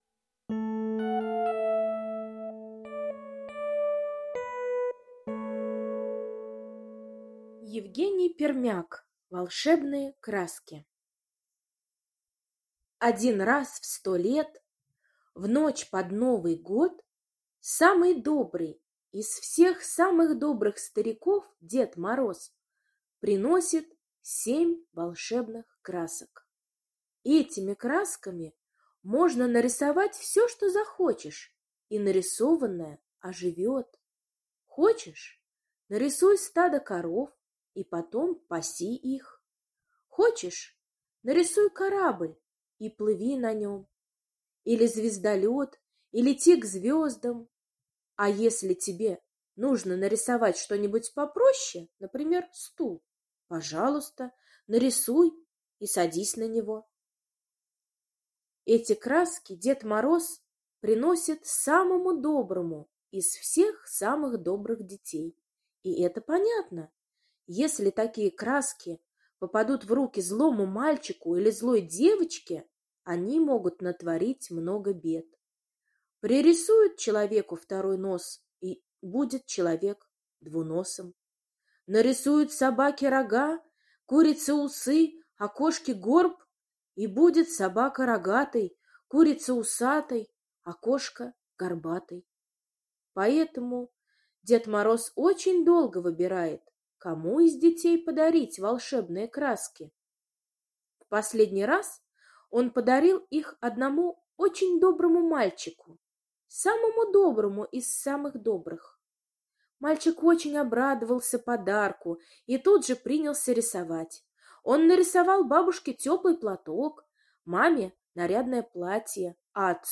Аудиосказка «Волшебные краски»